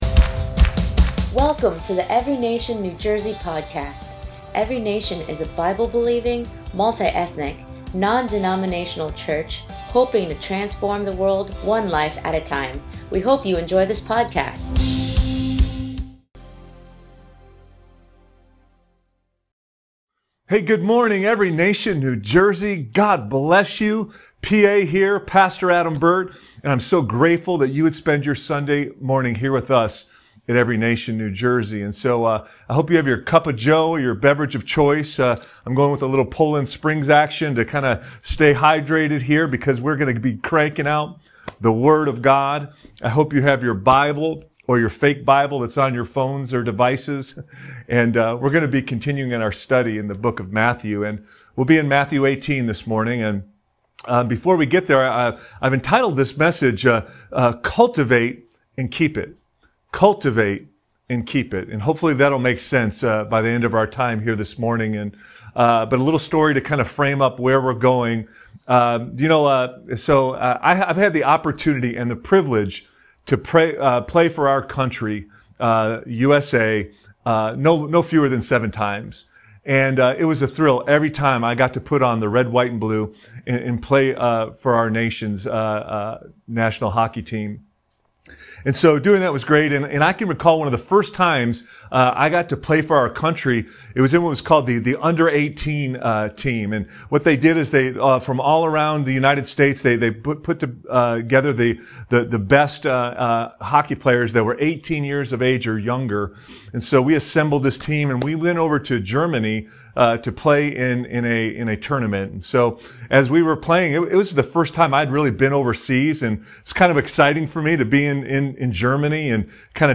ENCNJ Sermon 11/21/21